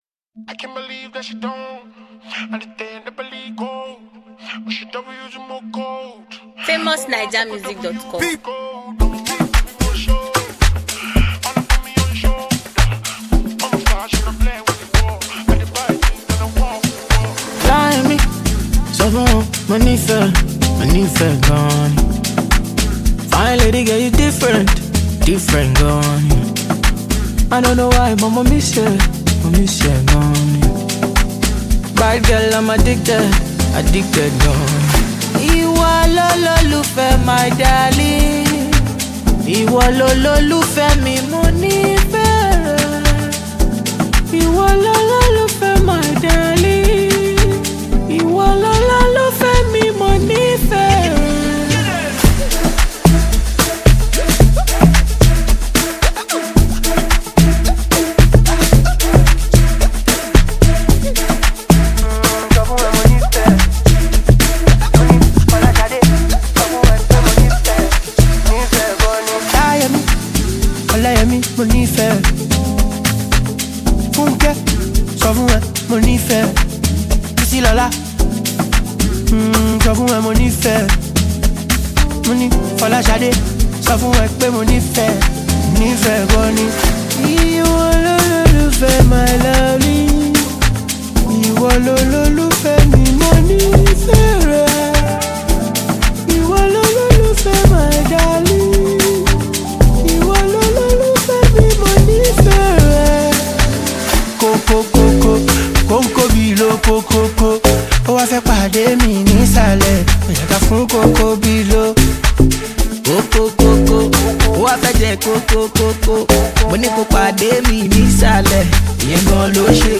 ” another tempting crush melody.